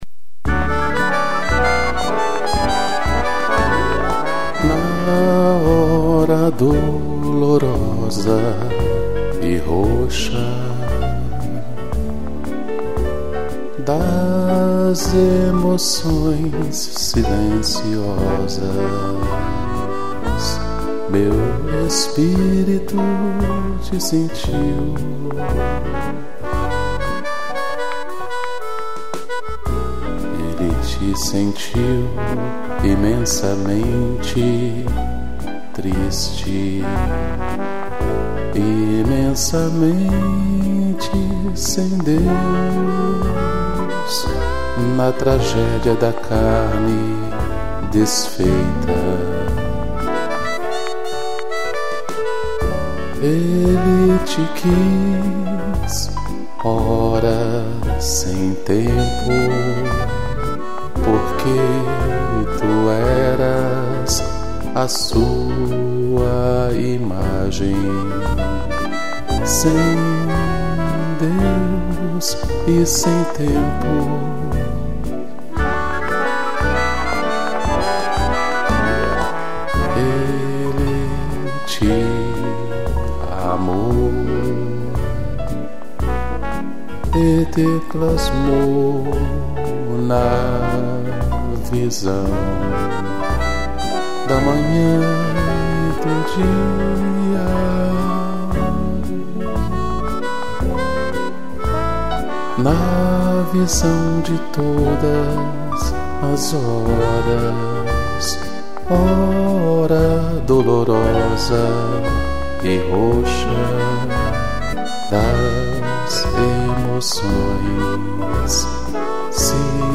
piano, gaita e trombone